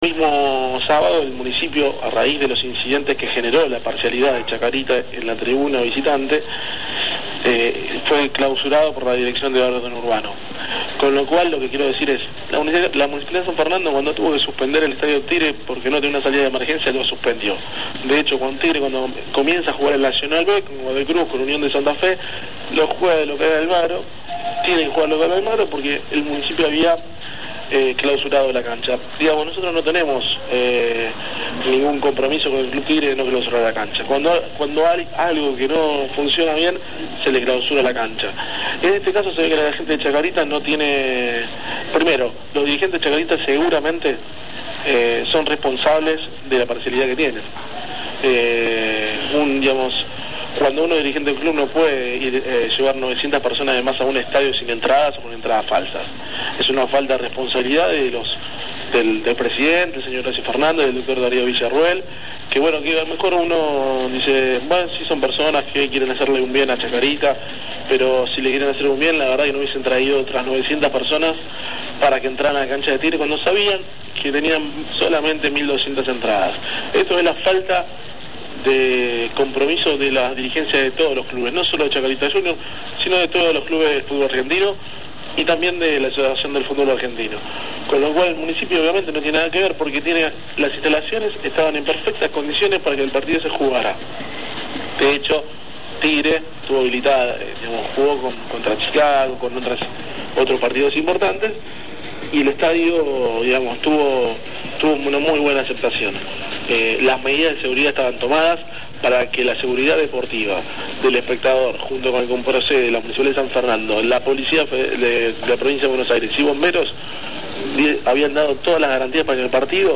En declaraciones realizadas en “El Informante” FM Open 99.5